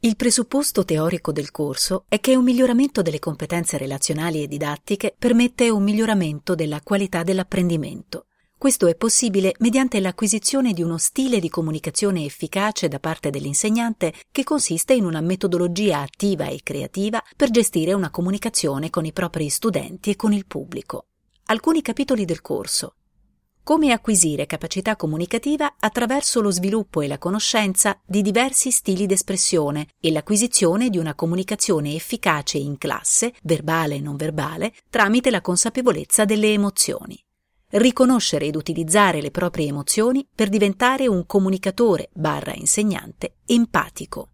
Giornalista speaker- Voice talent
Sprechprobe: Sonstiges (Muttersprache):